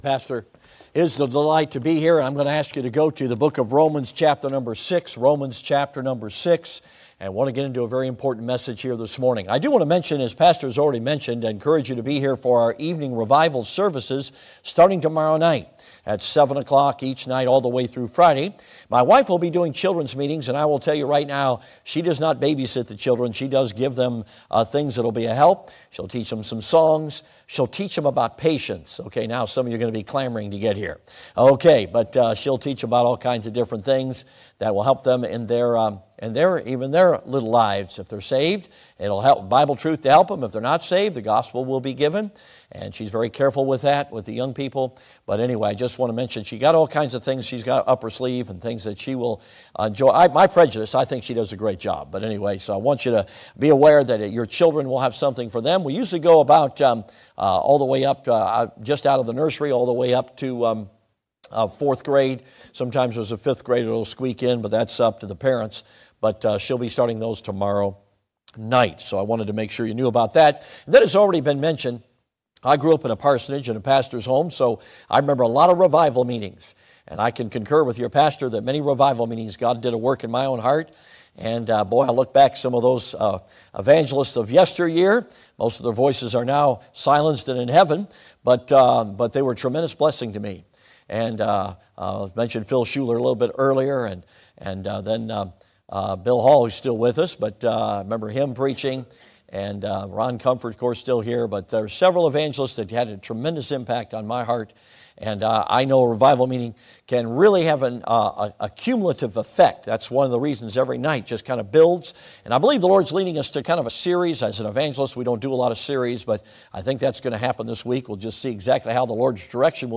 Rom. 6:23 Service Type: Sunday AM « The Way to Really Live is To Die Do You Know Who You Are?